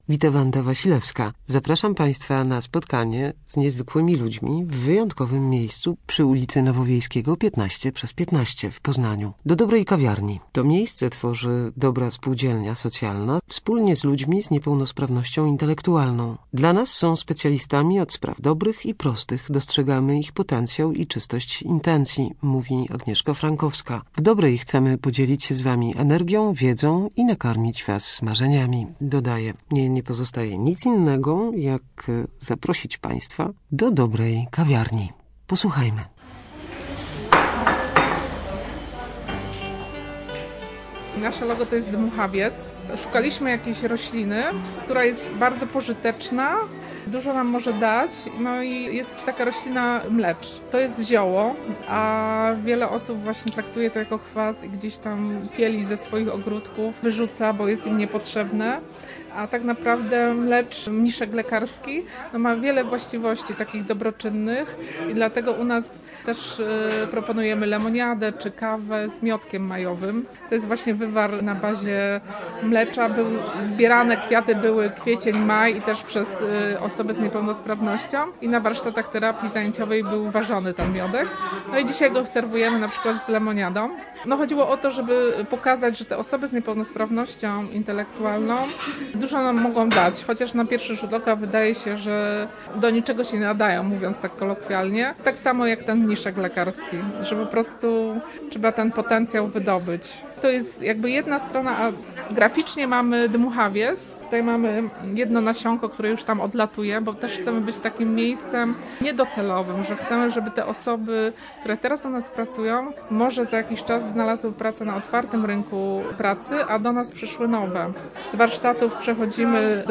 Dobra kawiarnia - reportaż